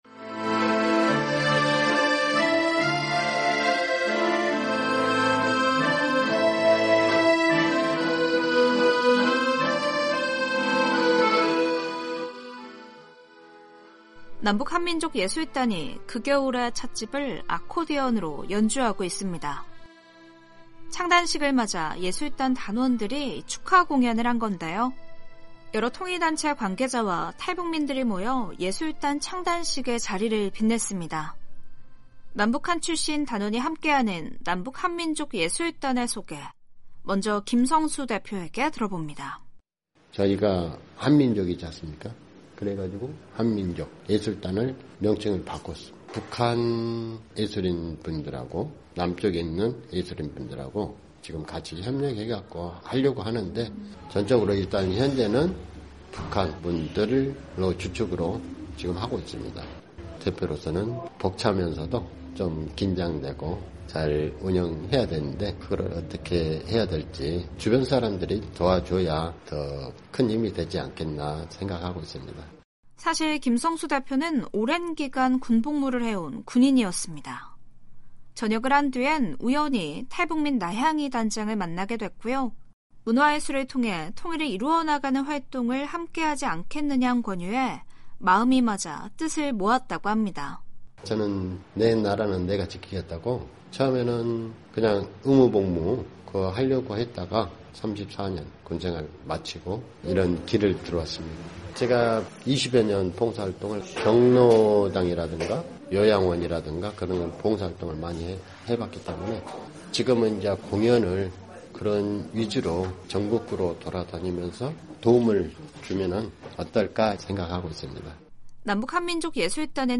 최근 '남북한민족예술단'의 새로운 출발을 알리는 창단식이 열렸습니다. 탈북민들의 다양한 삶의 이야기를 전해드리는 '탈북민의 세상 보기', 오늘은 남북한민족예술단 창단식 현장으로 안내해 드립니다.